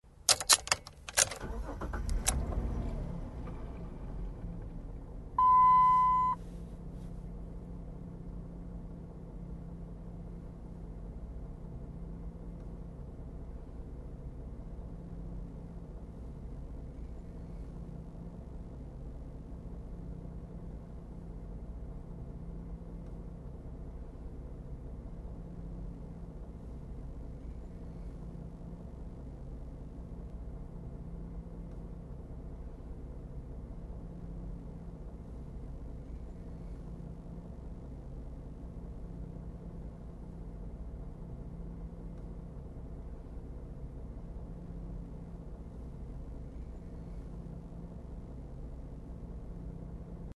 Звук в салоне автомобиля при запуске двигателя